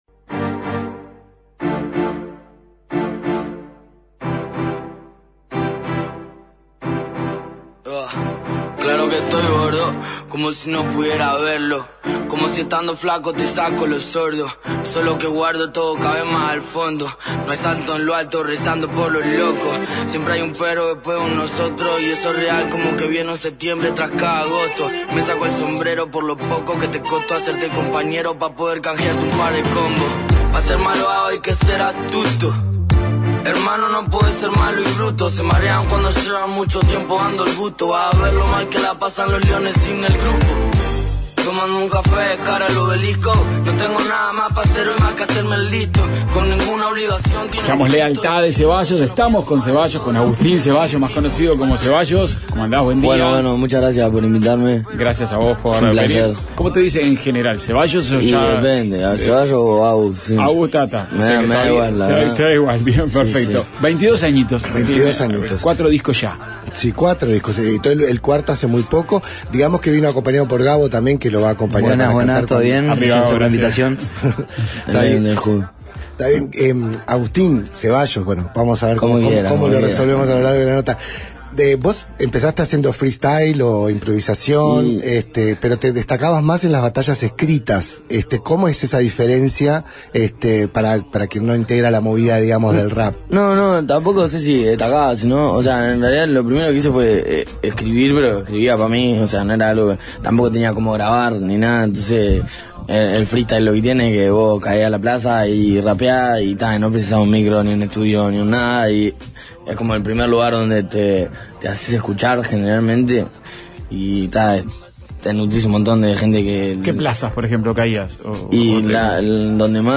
Entrevista y música en vivo